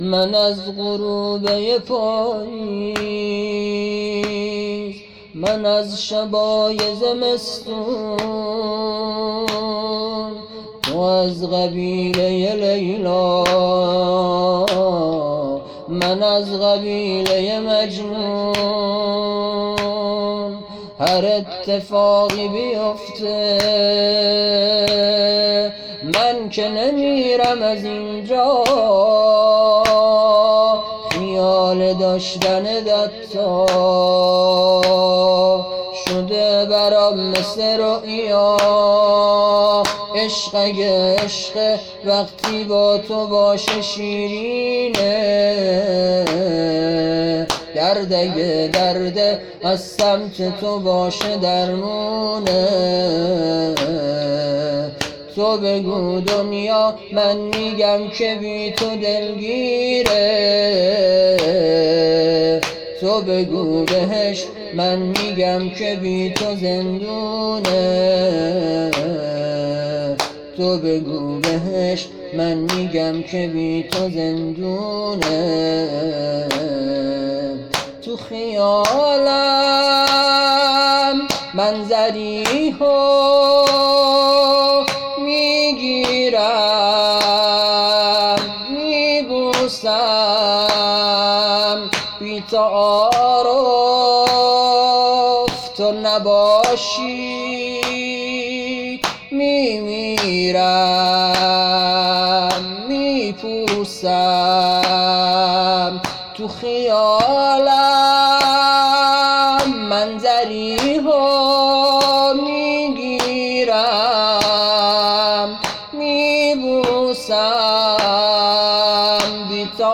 زمینه] - من از غروب یه پاییز - شب 5 محرم 1400
زمینه احساسی